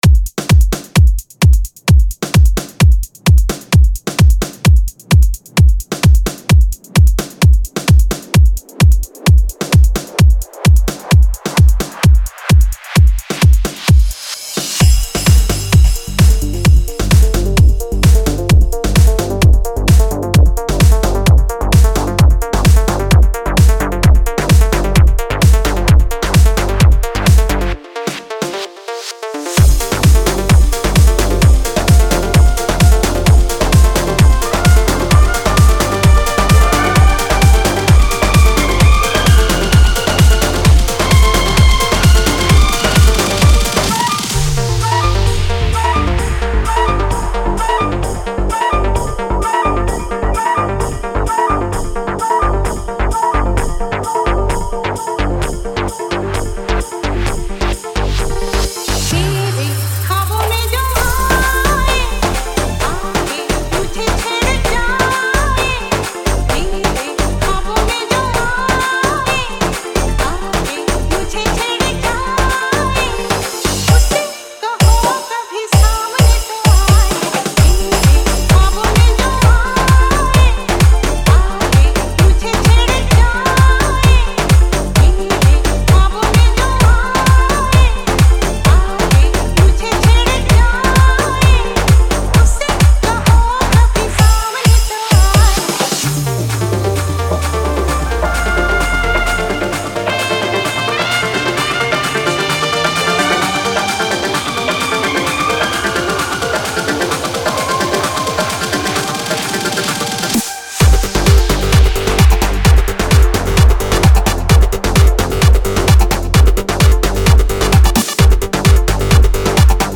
Singer : Old Hindi DJ Remix